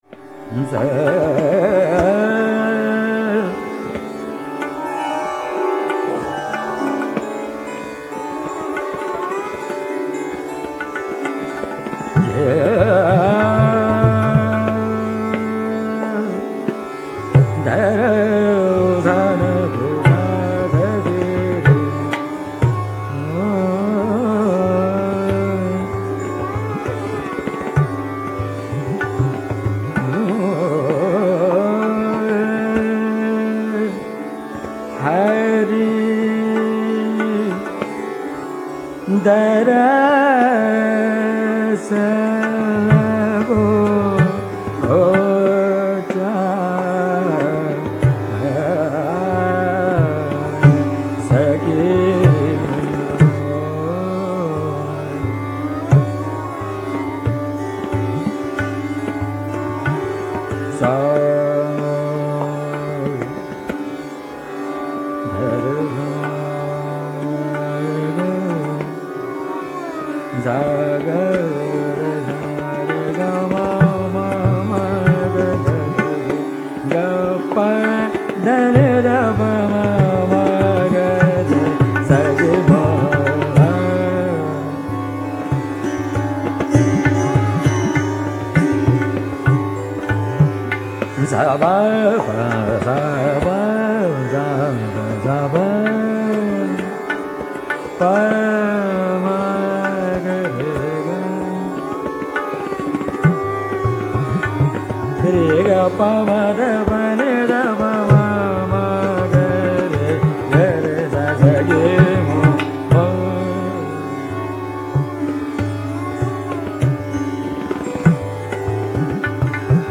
Mr. Jasraj uses both the madhyams and the shuddha dhaivat. Banditji‘s sargam reveals the melodic outline.